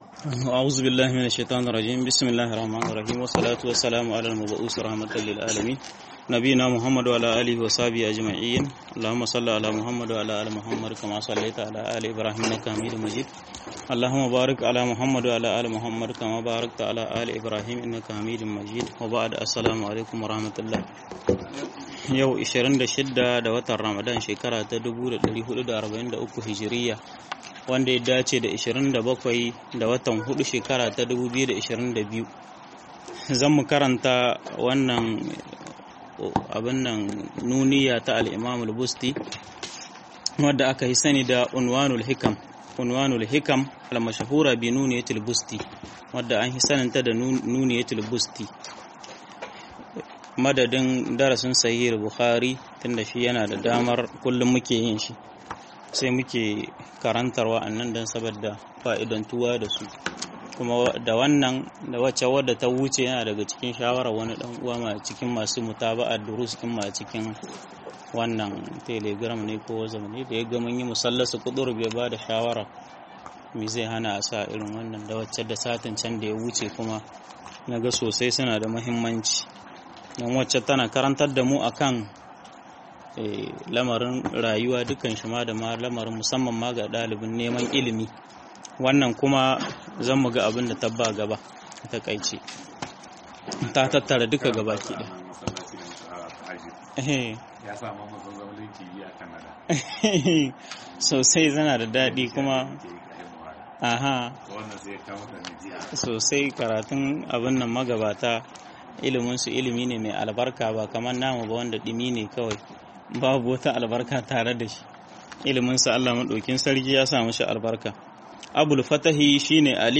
تائيتة أبي الفتح البستي - MUHADARA